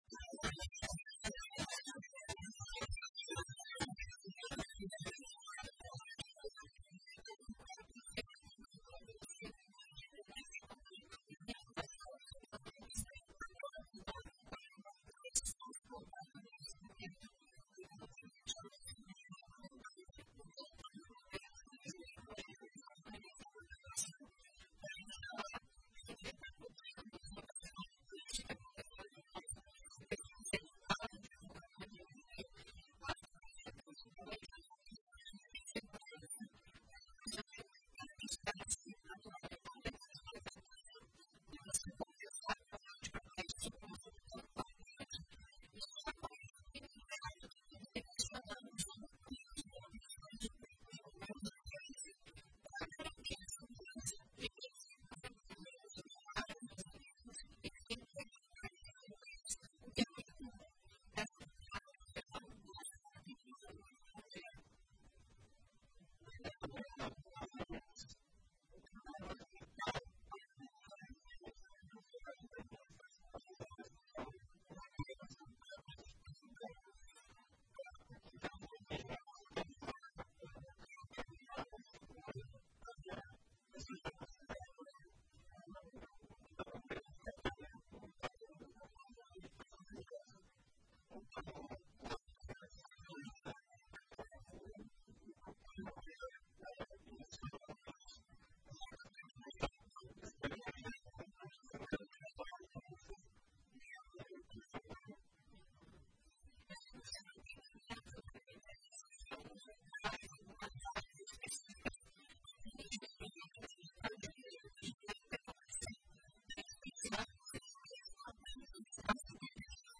Na entrevista completa disponível abaixo, você confere dicas sobre como proceder ao presenciar uma situação de afogamento.